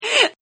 Female Hiccup Sound Effect Free Download
Female Hiccup